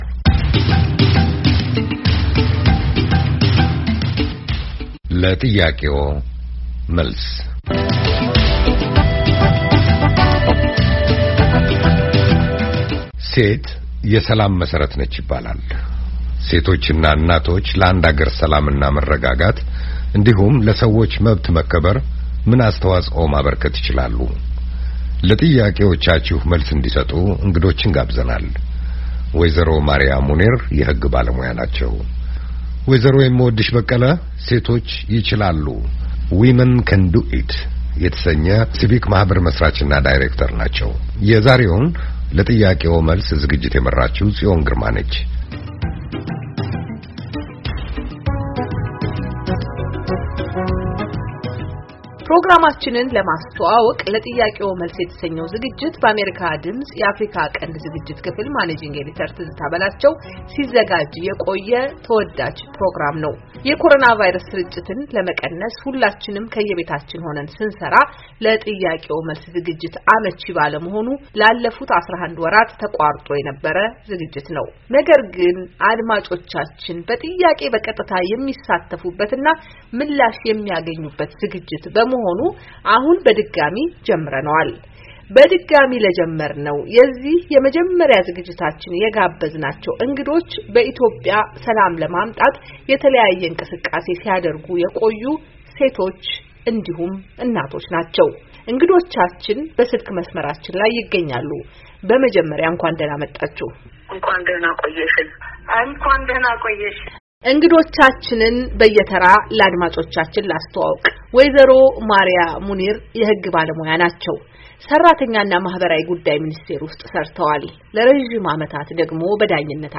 ሴቶች እና እናቶች ለአንድ አገር ሰላም እና መረጋጋት እንዲሁም ለሰዎች መብት መከበር ምን አስተዋፆ ማበርከት ይችላሉ? በጥያቄዎ መልስ ዝግጅታችን እንግዶችን ጋብዘን አወያይተናል።...